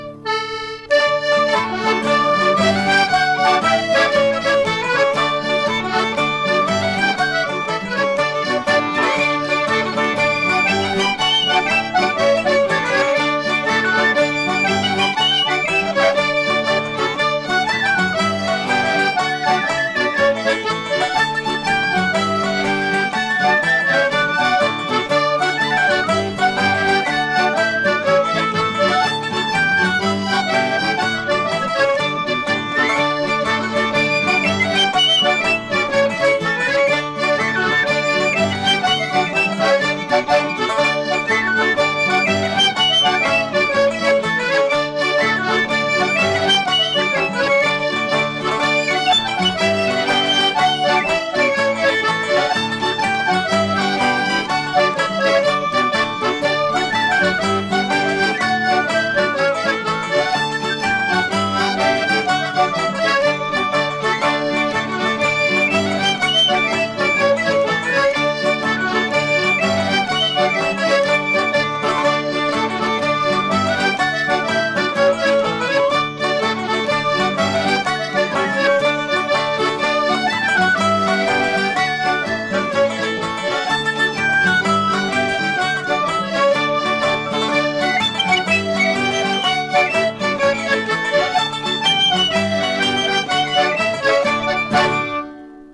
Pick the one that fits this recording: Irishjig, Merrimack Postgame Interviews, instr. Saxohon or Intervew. Irishjig